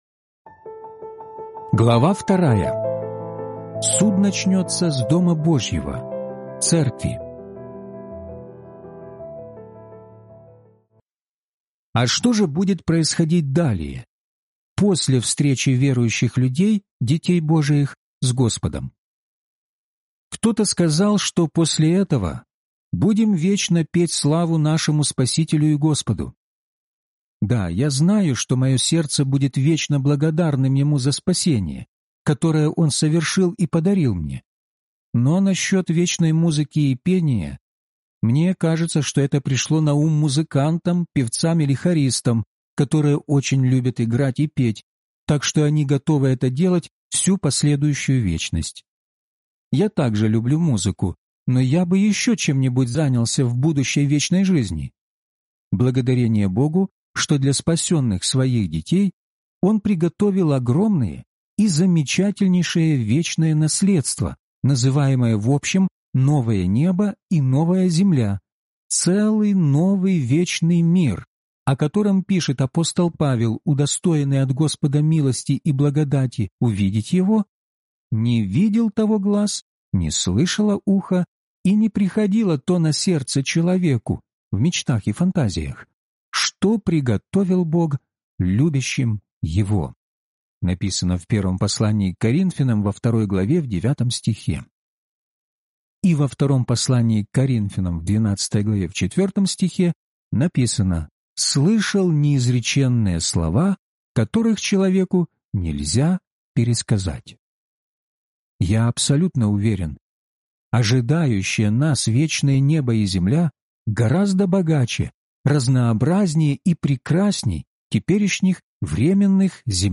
Судилище Христово (аудиокнига) - День 2 из 12